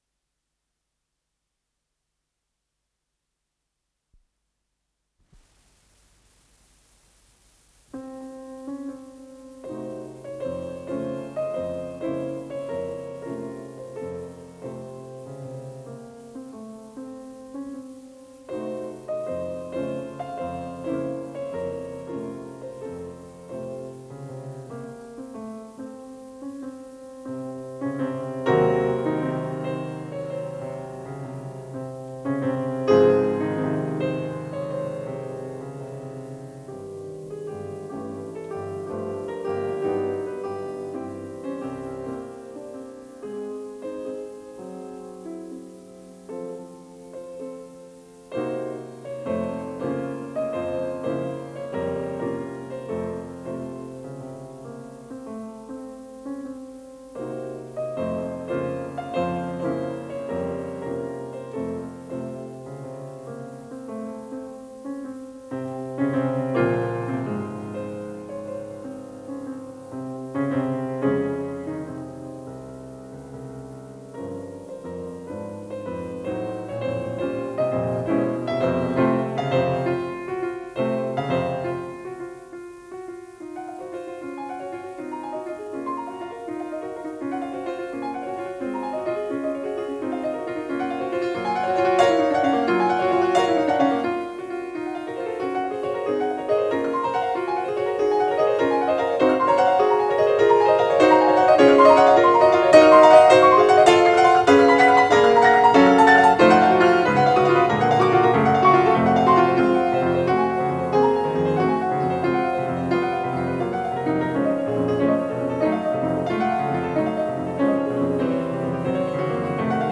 с профессиональной магнитной ленты
ИсполнителиСвятослав Рихтер - фортепиано
ВариантДубль моно